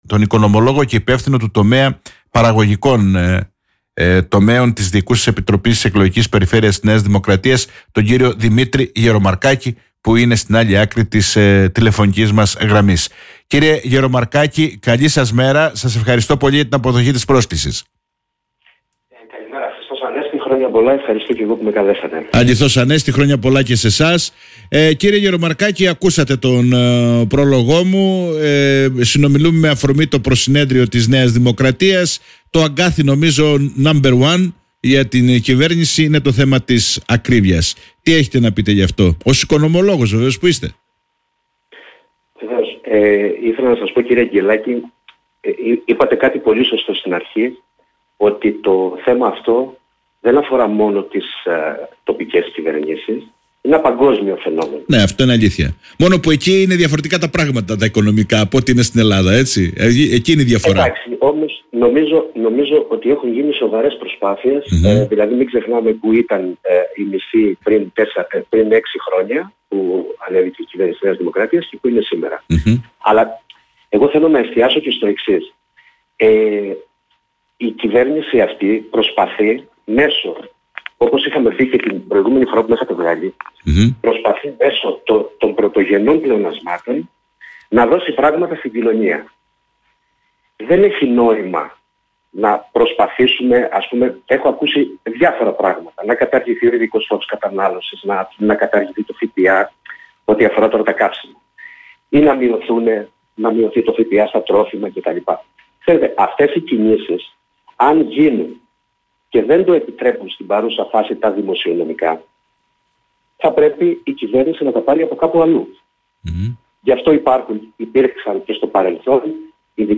Οικονομία, ακρίβεια, έργα υποδομών στην Κρήτη και πολιτικές αιχμές προς την αντιπολίτευση βρέθηκαν στο επίκεντρο της συνέντευξης του Οικονομολόγου και